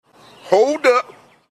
Hold Up - Comedy Sound Effect
Hold-up-Comedy-sound-effect.mp3